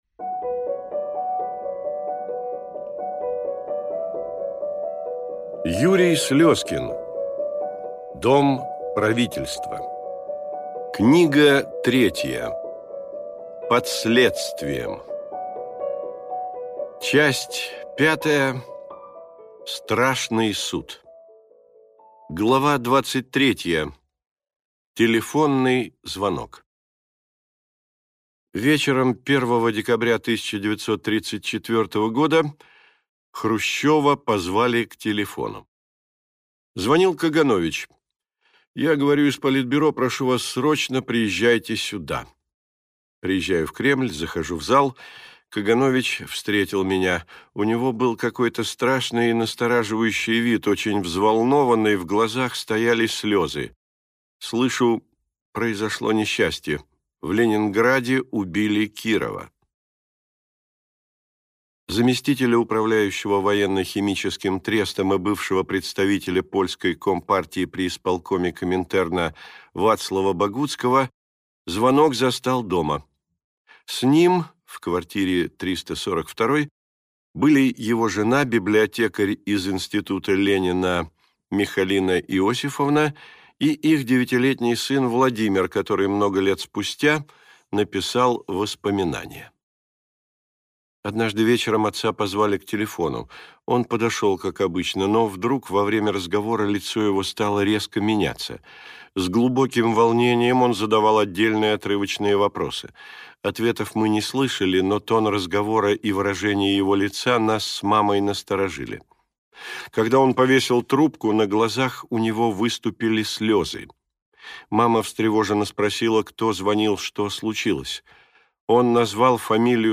Аудиокнига Дом правительства. Сага о русской революции. Книга третья. Под следствием | Библиотека аудиокниг